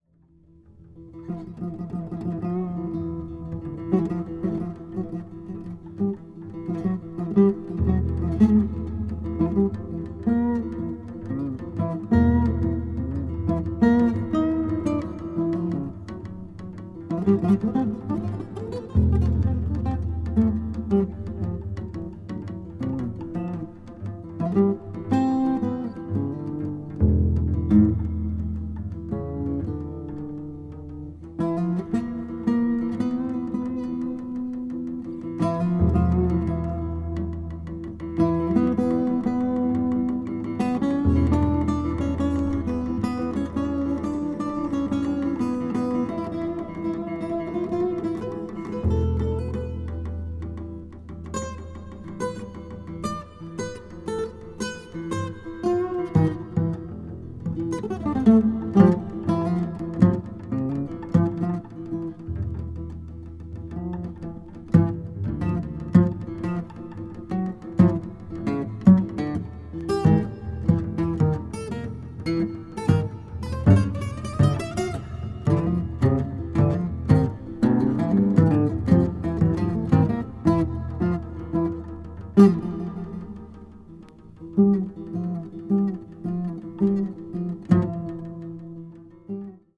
奏でられるすべての音がじんわりと染みてくるとても静かな世界